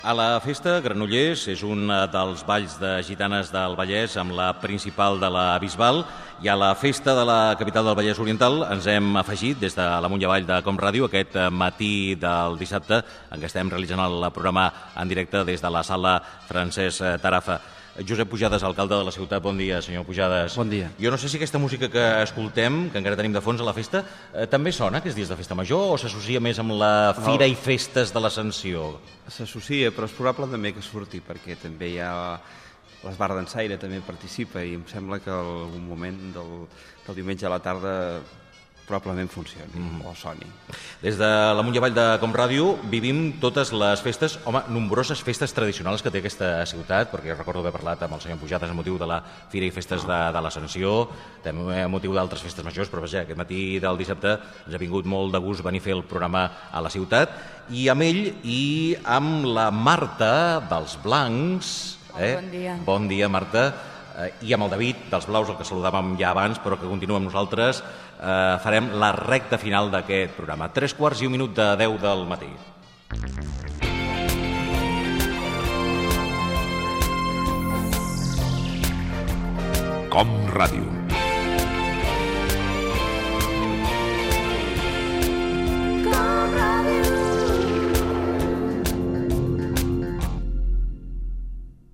Salutació a l'alcalde de Granollers Josep Pujades. Especial emès des de Granollers, amb motiu de la festa major.
Entreteniment